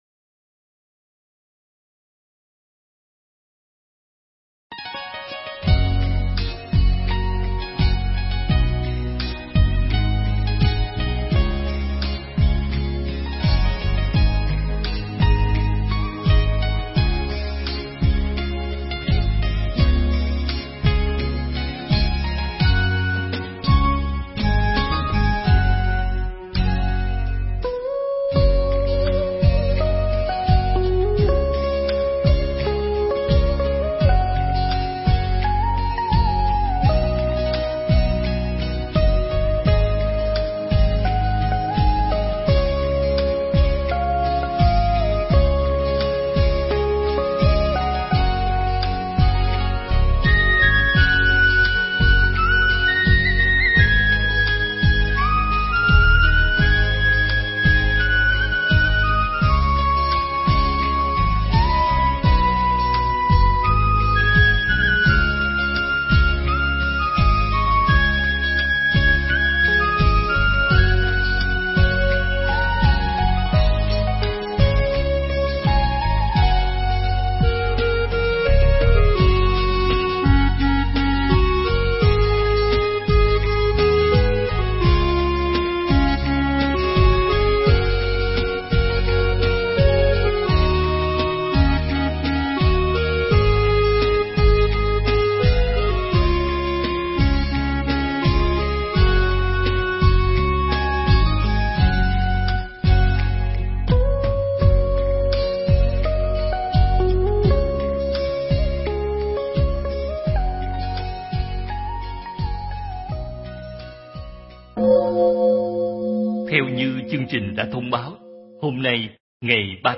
Nghe Mp3 thuyết pháp Gieo Hạt
Nghe mp3 pháp thoại Gieo Hạt